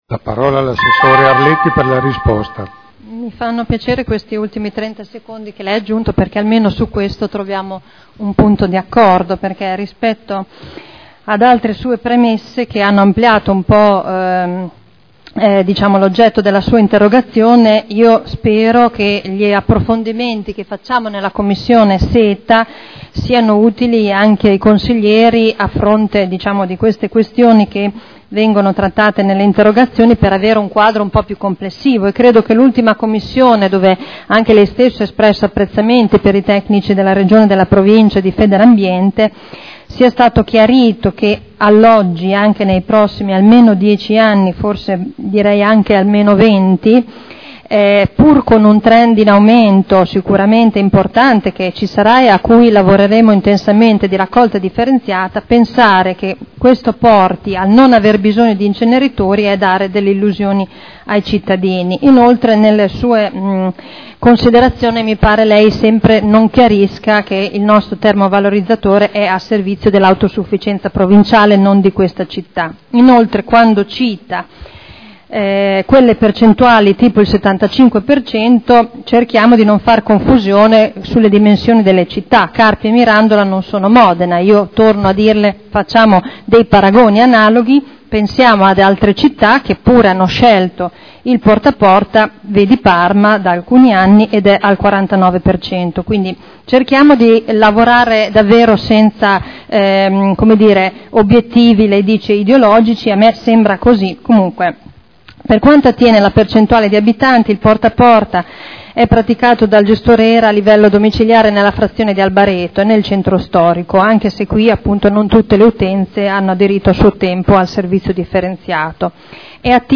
Seduta del 14/03/2011.